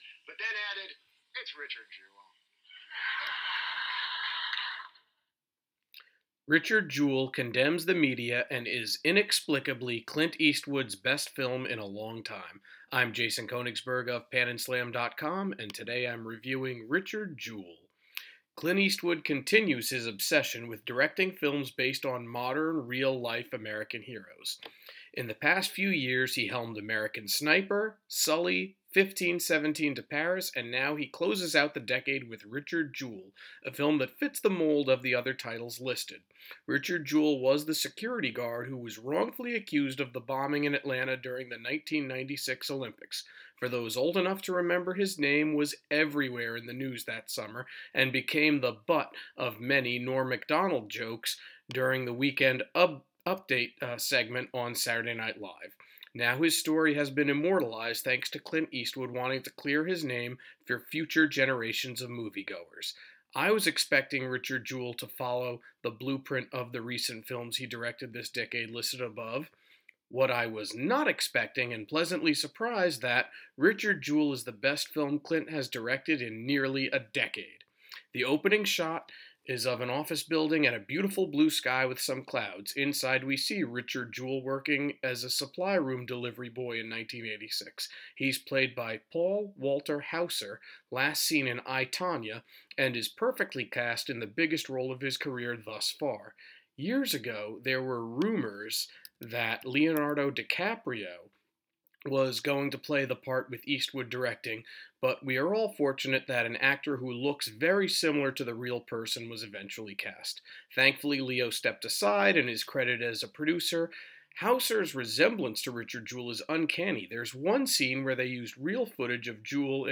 Movie Review: Richard Jewell